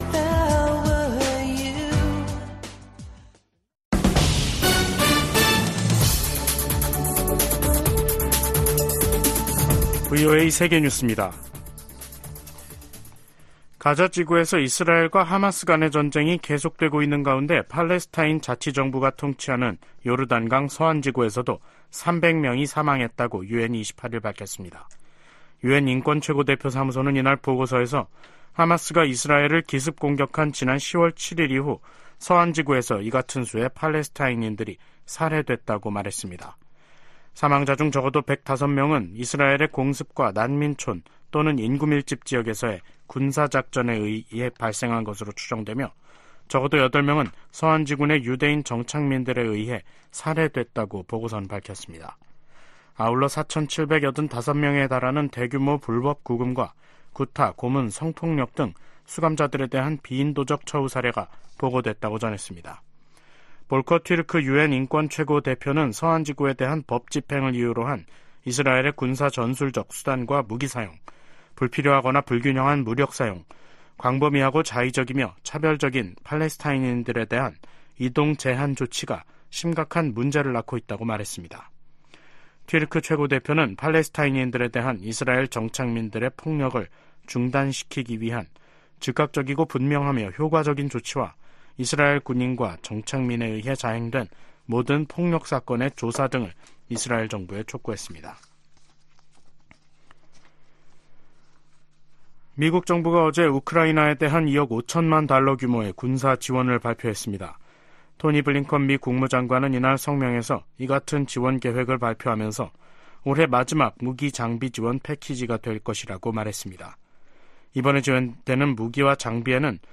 VOA 한국어 간판 뉴스 프로그램 '뉴스 투데이', 2023년 12월 28일 3부 방송입니다. 김정은 북한 국무위원장이 당 전원회의서 전쟁 준비에 박차를 가하는 전투과업을 제시했습니다. 미 국무부가 북한의 중요 정치행사에 등장한 벤츠 행렬에 대해 대북 제재의 운송수단 반입 금지 의무를 상기시켰습니다. 전 주한미군사령관들이 신년 메시지를 통해 미한 연합훈련과 가치 동맹의 확대를 주문했습니다.